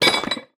UI_BronzeKick_Roll_01.ogg